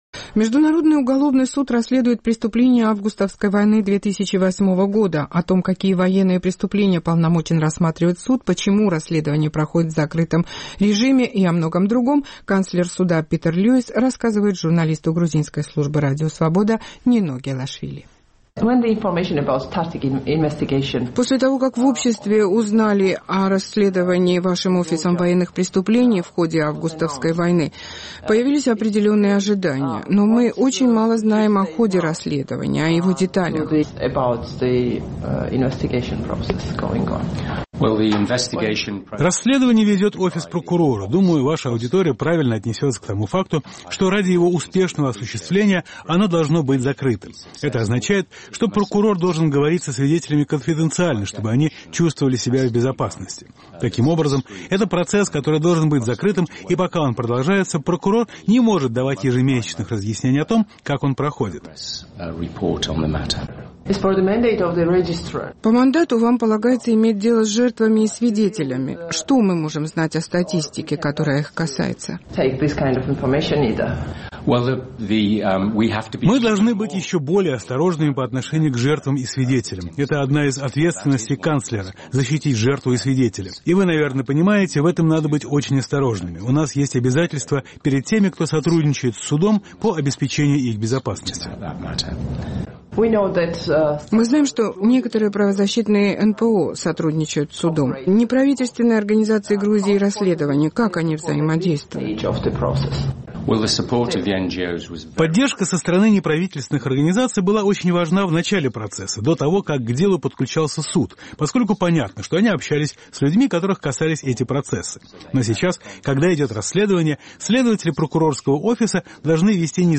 Питер Льюис, канцлер Международного уголовного суда, разъясняет грузинской службе Радио Свобода некоторые вопросы, касающиеся расследования преступлений августовской войны 2008 года.